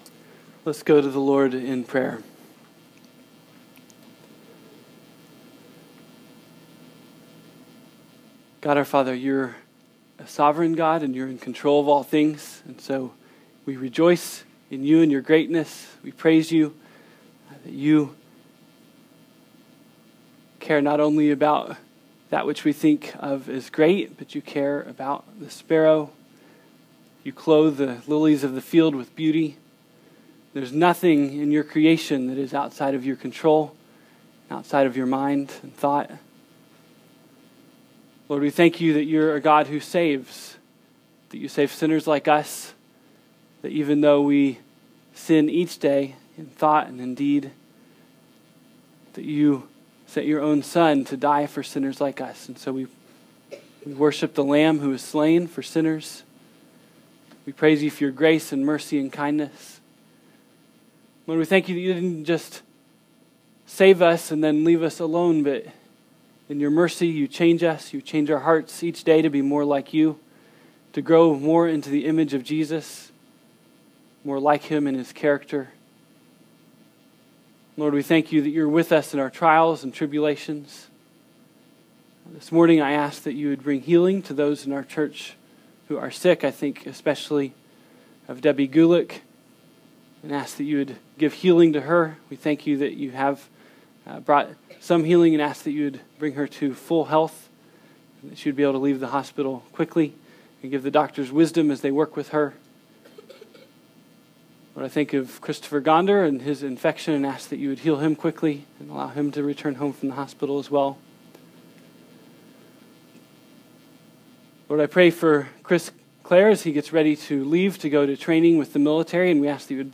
Bible Text: Acts 25:1-26:32 | Preacher